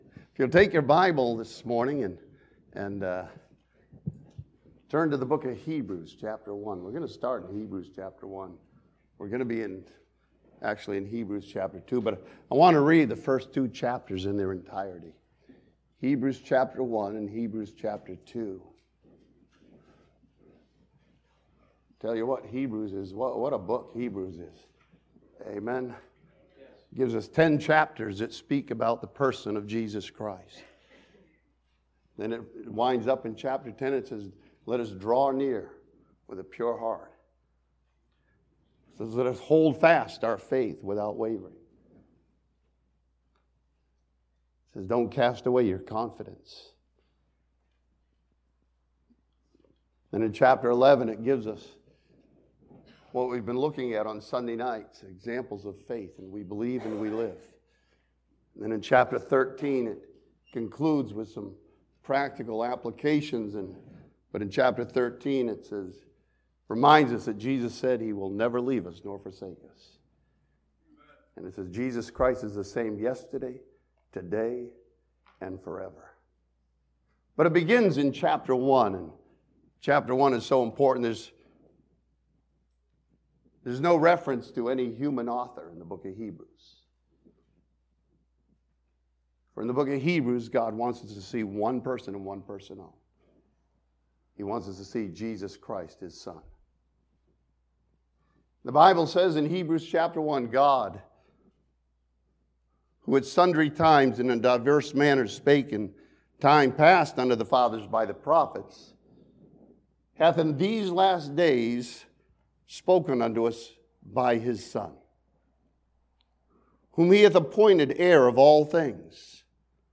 This sermon from the first two chapters of Hebrews challenges believers to appreciate and understand the so great salvation we have.